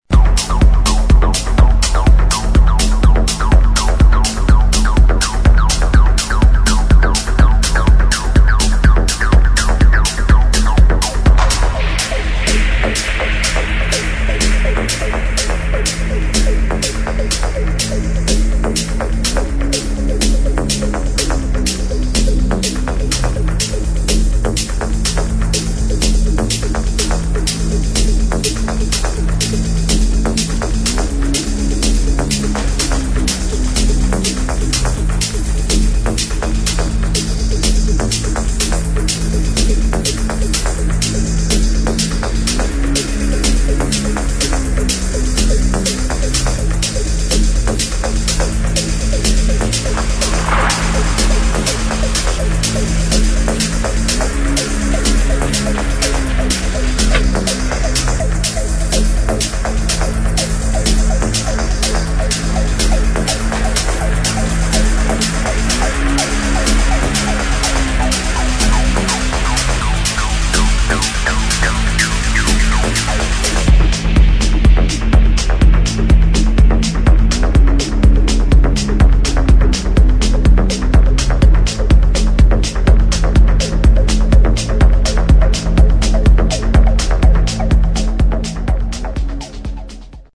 [ TECHNO ]